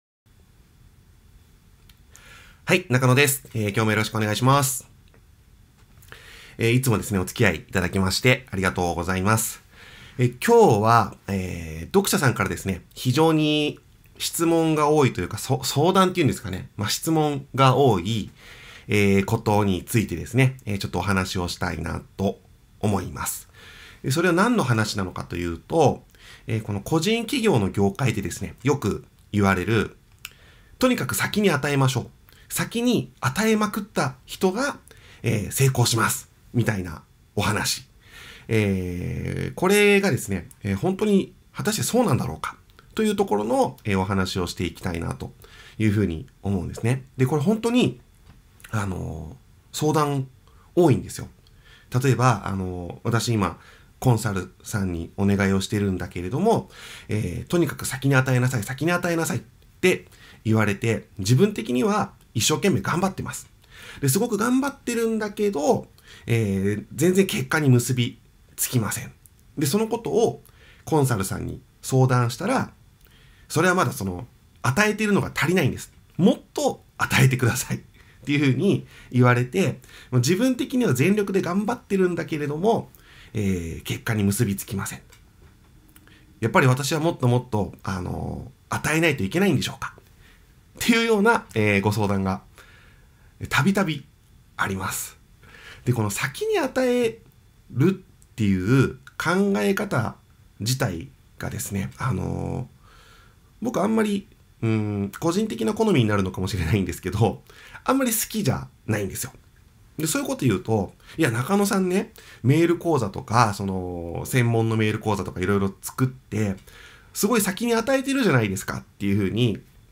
今日も僕からプレゼントをさせていただきます♪ 今日のプレゼントセミナーは、 「先に与えるのって本当に大事なの？セミナー」 です（笑） 個人ビジネス業界だと、 まるでそれが当たり前かのように「先に与えるのが大事」って言われます。